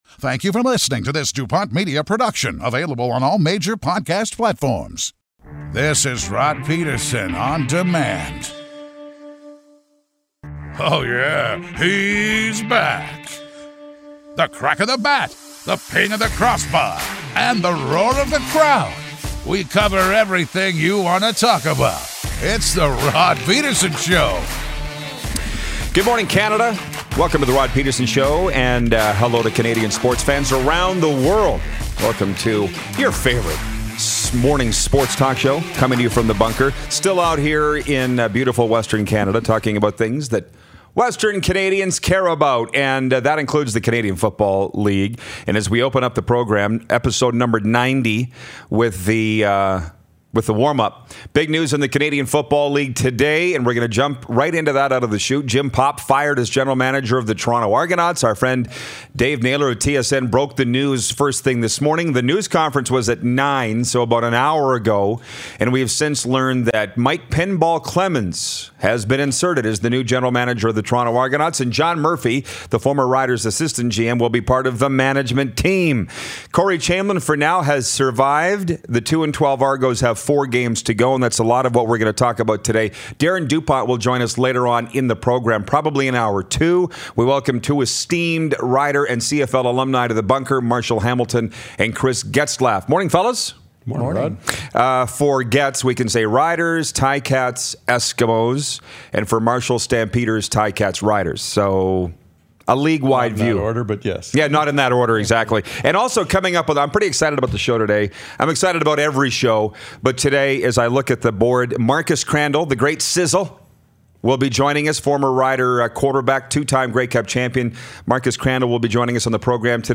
Coffee is brewing, and so is sports talk!
Roughrider great, Chris Getzlaf joins us in studio!
Former CFL quarterback and coach, Marcus Crandell calls in!